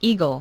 eagle.mp3